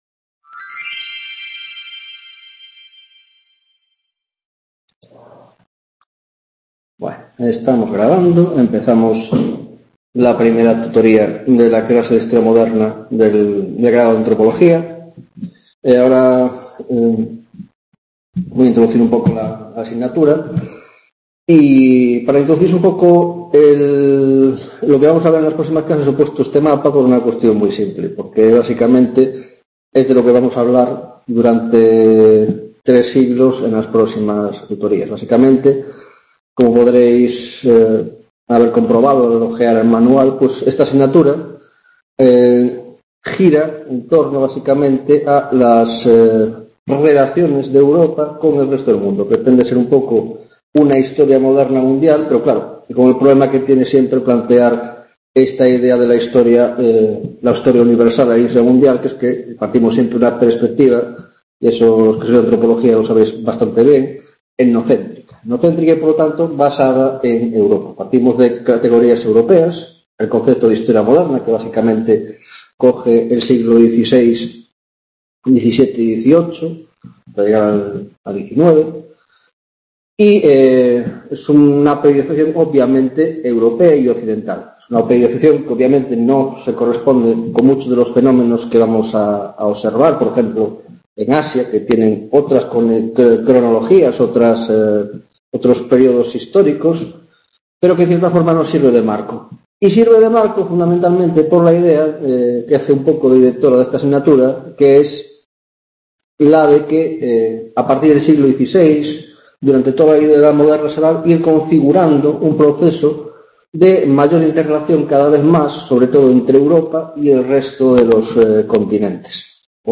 1ª tutoría de Historia Moderna, Grado de Antropología - Introducción: Edad Moderna en Europa s. XVI - Demografía, Sociedad y Economía (1ª Parte)